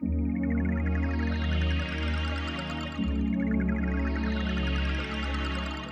01F-PAD-.A-L.wav